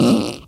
文件夹里的屁 " 屁 46
描述：从freesound上下载CC0，切片，重采样到44khZ，16位，单声道，文件中没有大块信息。
Tag: 喜剧 放屁 效果 SFX soundfx 声音